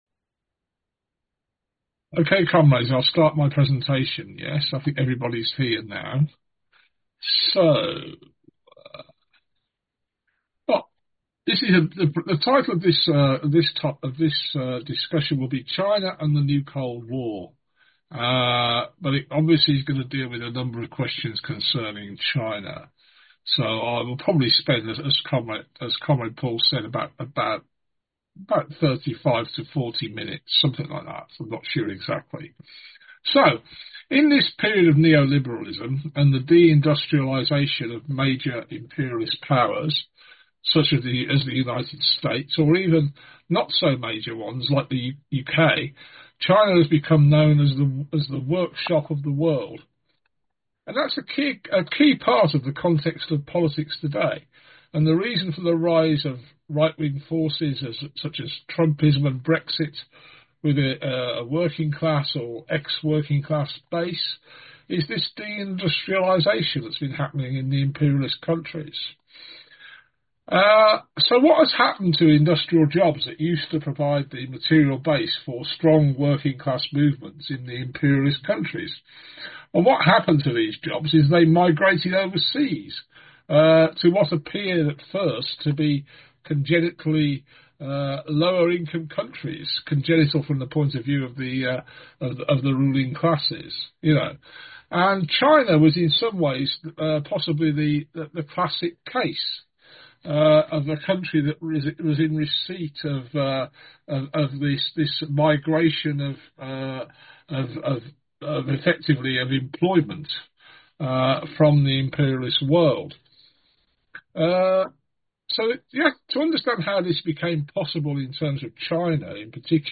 Below is a presentation given by a Consistent Democrats speaker at a Zoom forum on 4th May.